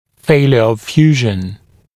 [‘feɪljə əv ‘fjuːʒ(ə)n][‘фэйлйэ ов ‘фйу:ж(э)н]нарушение срастания (напр. о расщелине нёба)